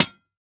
Minecraft Version Minecraft Version 1.21.5 Latest Release | Latest Snapshot 1.21.5 / assets / minecraft / sounds / block / lantern / break4.ogg Compare With Compare With Latest Release | Latest Snapshot
break4.ogg